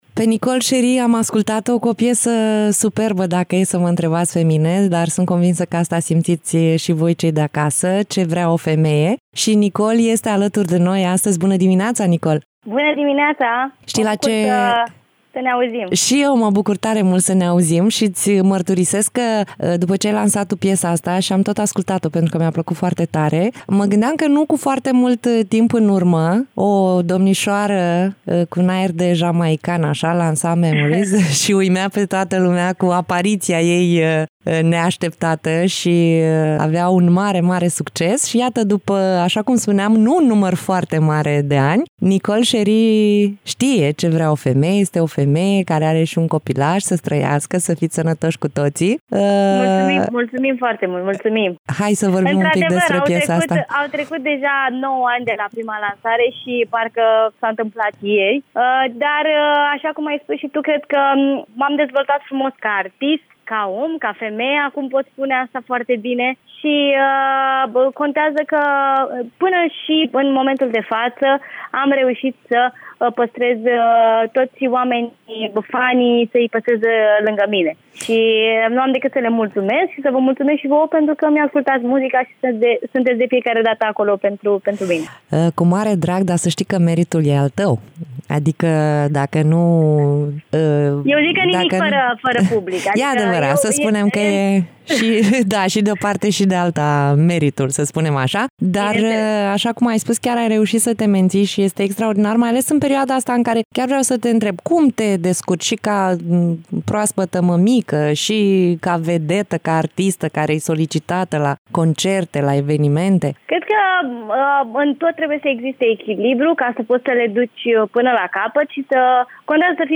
INTERVIU
Interviu-Nicole-Cherry.mp3